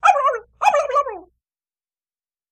Animals-barnyard|Turkey | Sneak On The Lot
Turkey gobble ( fake )